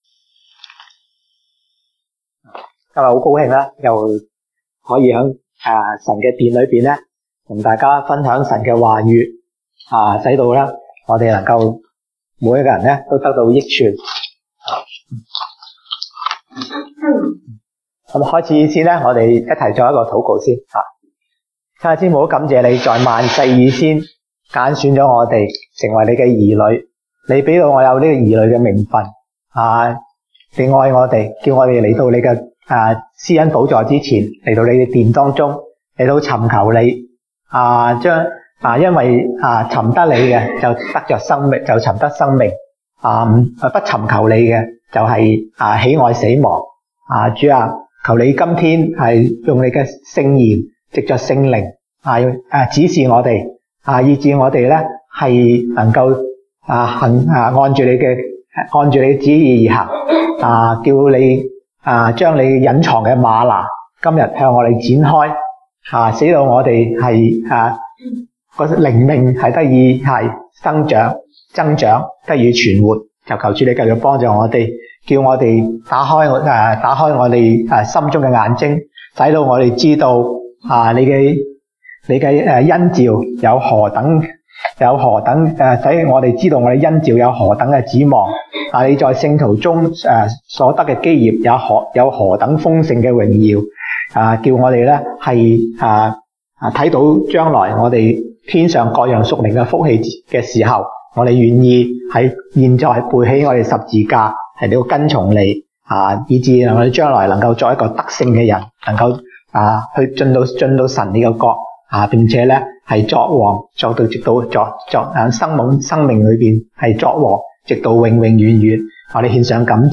東北堂證道 (粵語) North Side: 永活的神與永遠的國